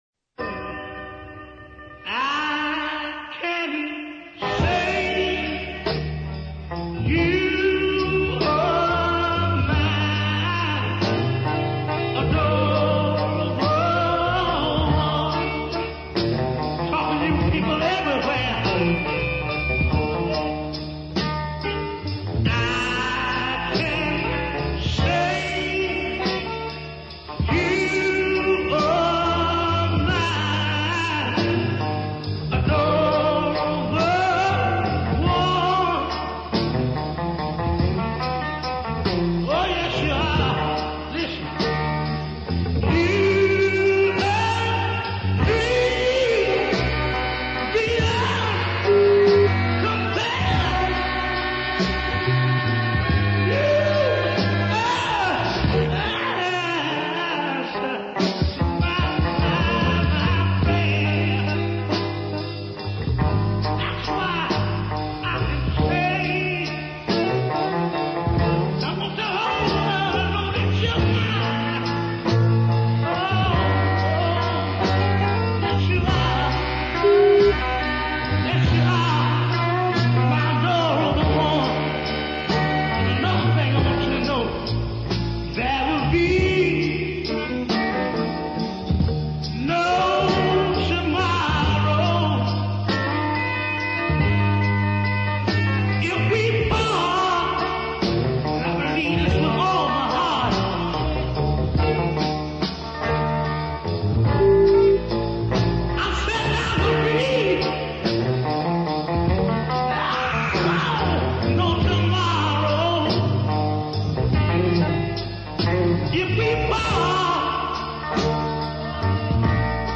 deep soul music